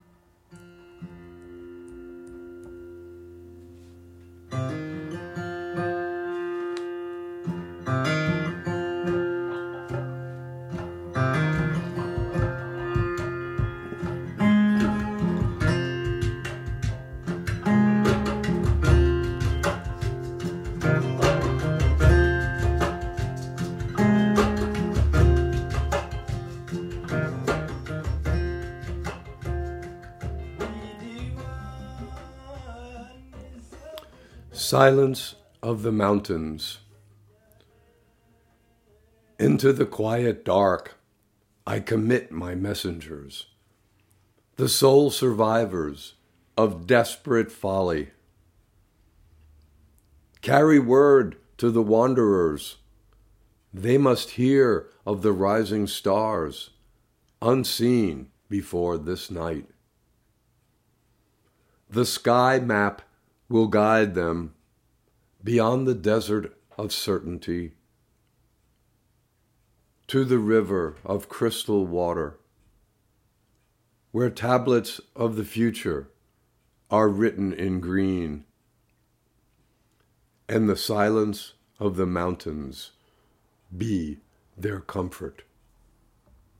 Reading of “Silence of the Mountains” with music by Tinariwen